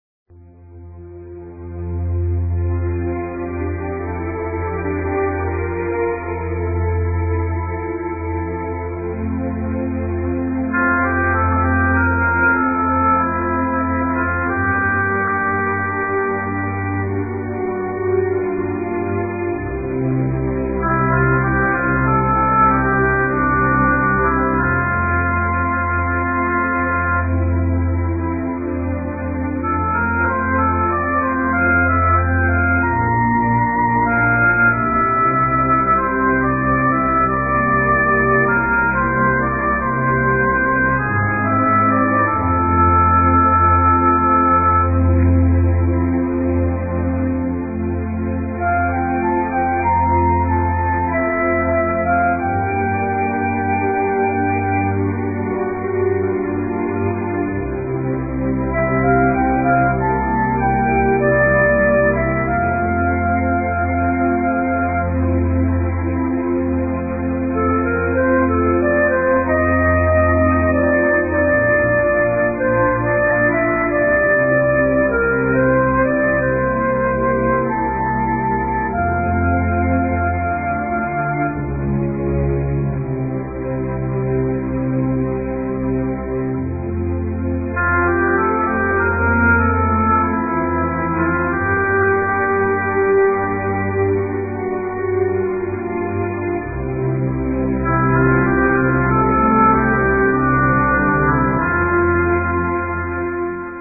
ORQUESTAS